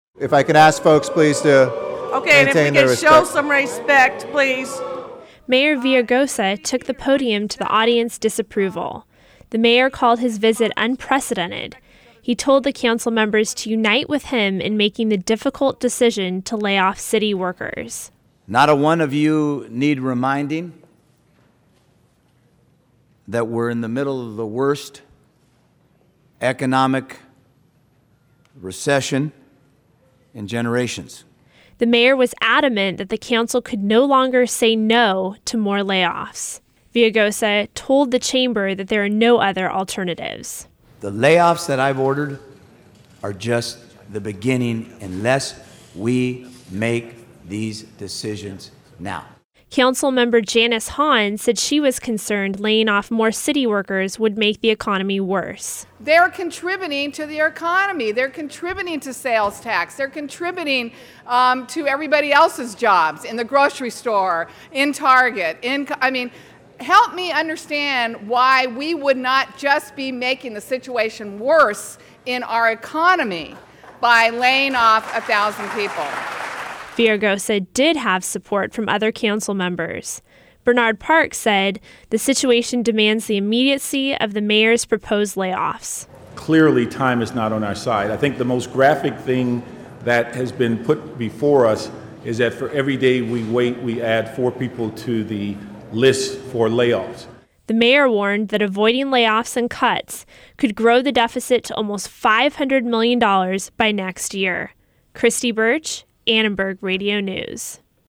Mayor Villairagosa took the podium to the audience's disapproval and attempted to get city council members to unite with him in making the difficult decision to lay off city workers.